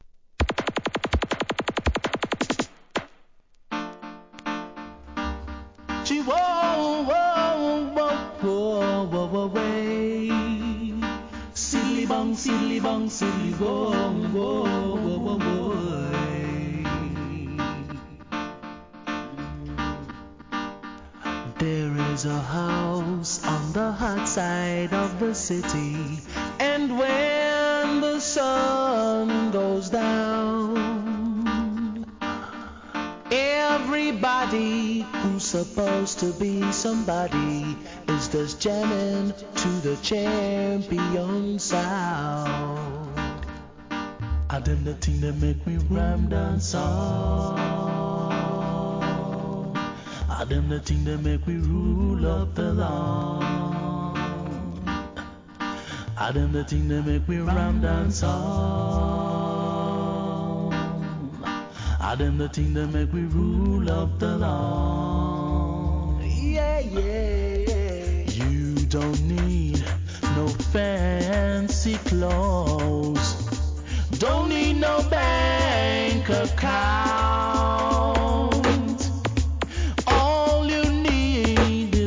REGGAE
渋いマイナー調で名曲カヴァー♪